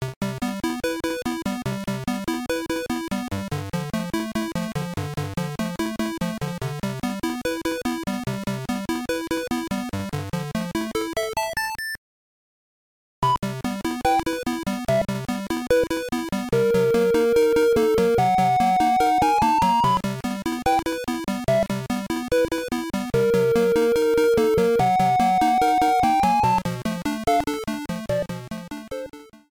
Trimmed to 30 seconds and applied fade-out when needed